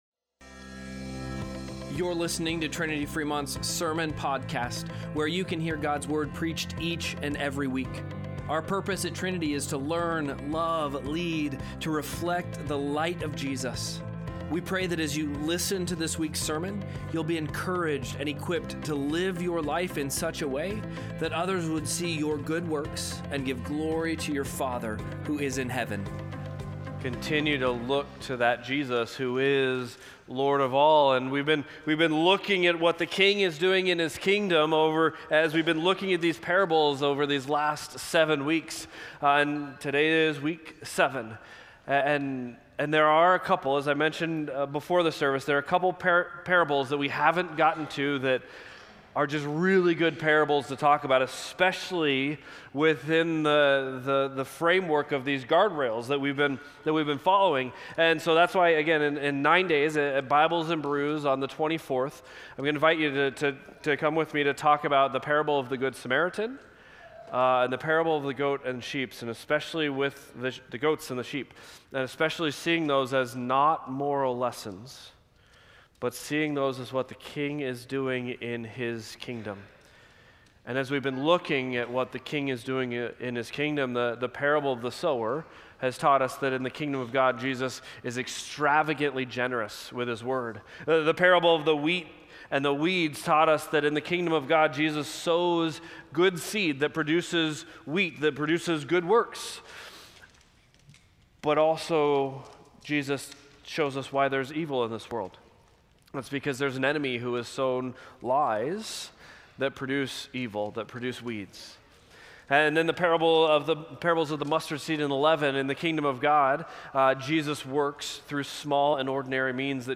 Sermon-Podcast-2-15.mp3